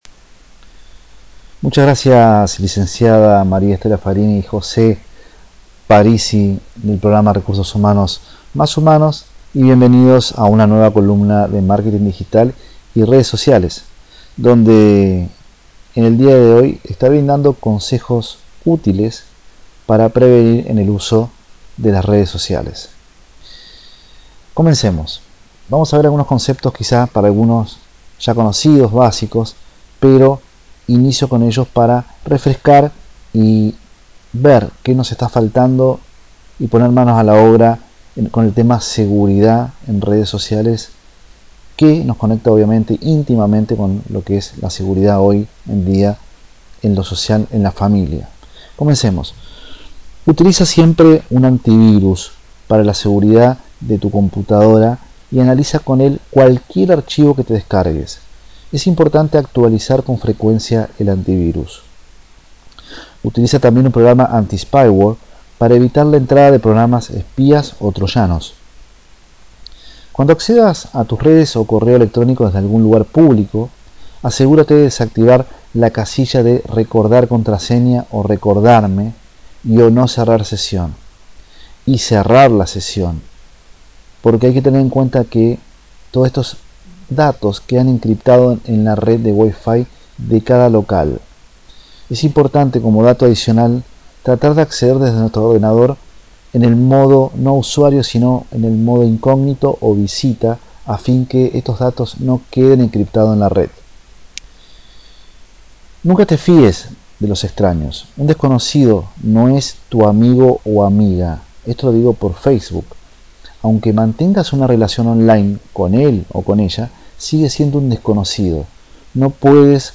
En este nuevo audio grabado con la columna de Marketing Digital y Redes Sociales para el Programa Radial Recursos Humanos + Humanos, comparto contigo sencillos Consejos para el Uso y Prevención en la Redes Sociales.